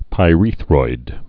(pī-rēthroid, -rĕthroid)